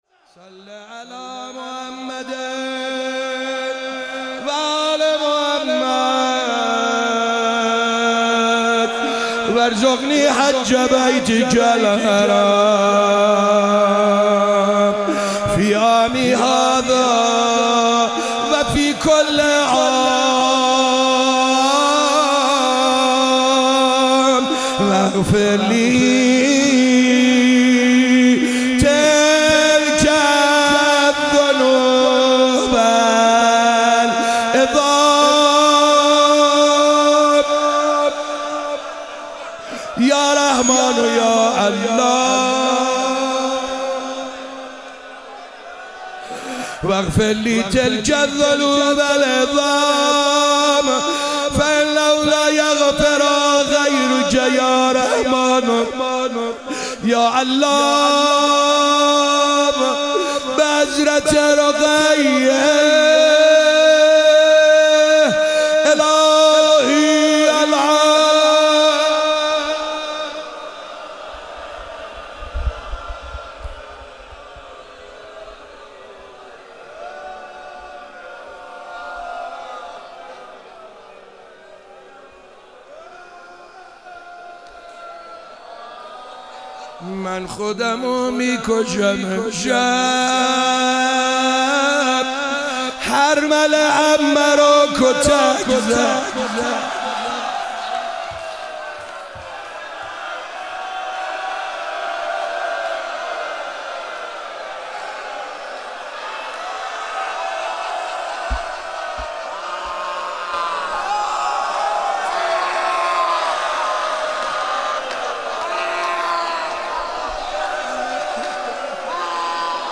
شب 3 ماه رمضان97- دعا خوانی - دعای افتتاح